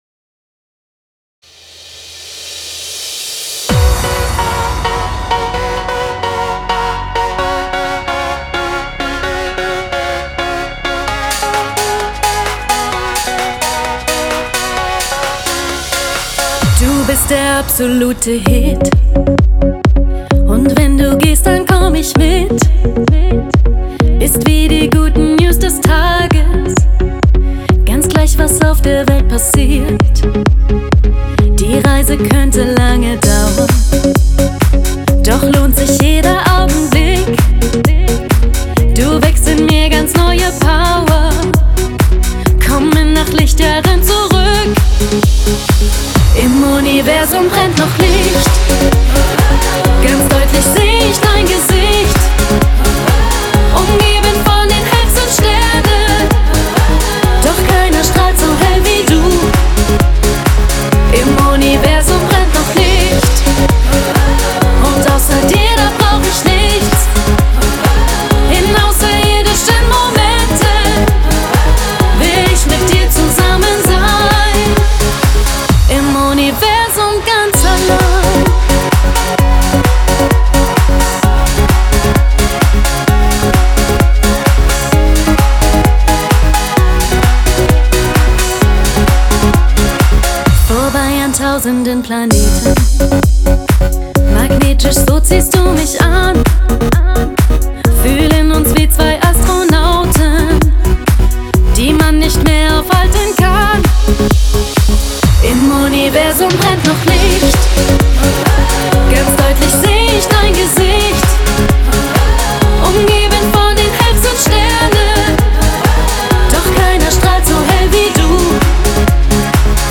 Жанр: Schlager
Genre: Schlager